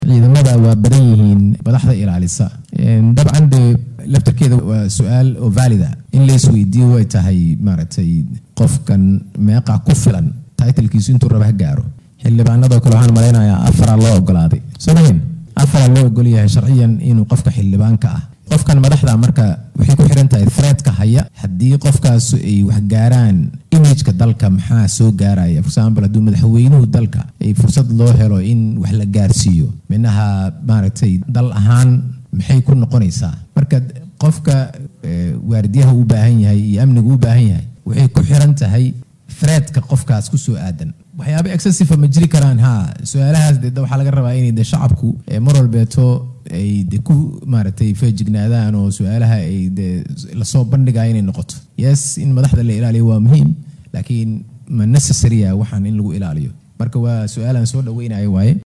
La taliyaha Amniga Qaranka ee Madaxweynaha Jamhuuriyadda Federaalka Soomaaliya Xuseen Macalin oo ka jawaabayay su’aallo lagu waydiiyay dood uu xalay ka qayb galay ayaa sheegay inay jiraan tallaabooyin ku aaddan xakameynta hubka .